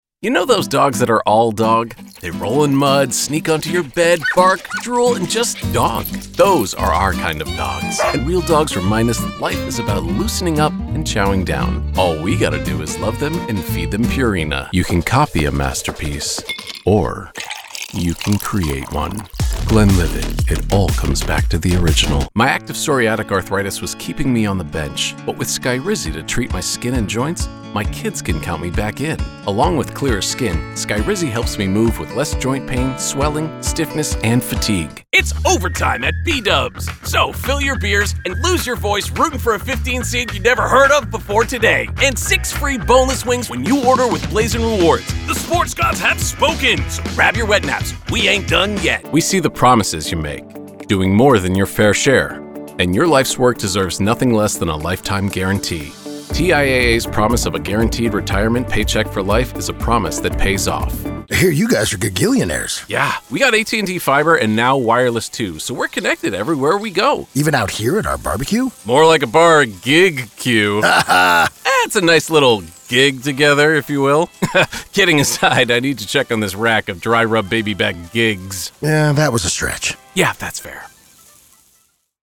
Male
English (North American)
Adult (30-50)
Clear, warm, articulate, confident and conversational, my voice resonates with trust and clarity, delivering your message with authentic sincerity and adaptable professionalism.
My Commercial Demo
Male Voice Over Talent